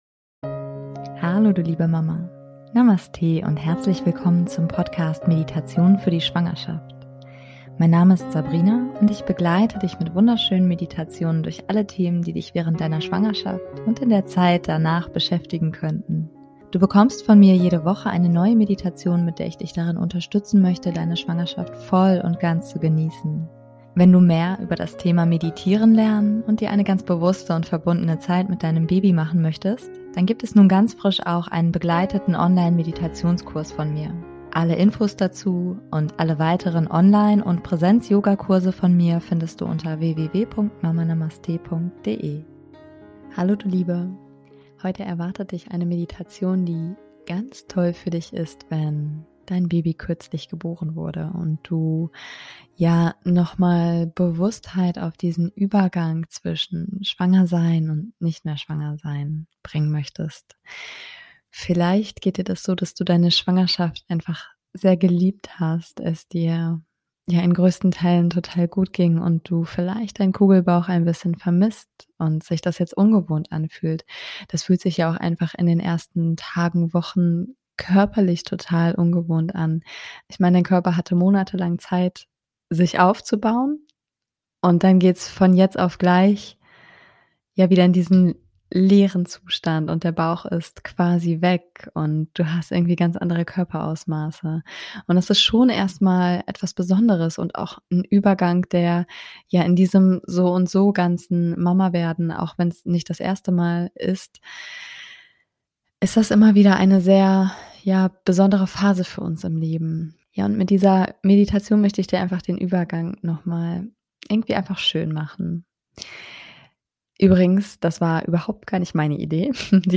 #118 - Übergang von schwanger zu nicht mehr schwanger - Meditation